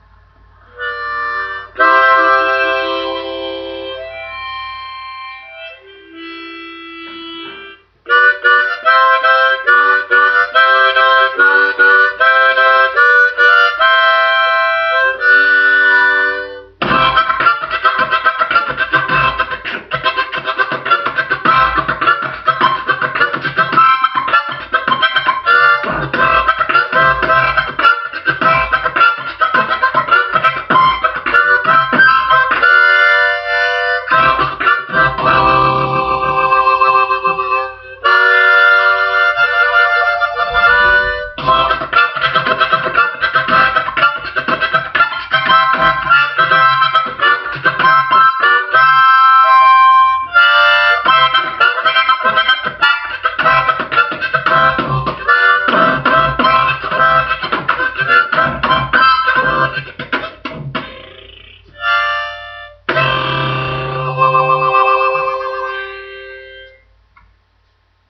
просто импровизация. с нетерпением жду ваших отзывов дорогия друзья и товарищи!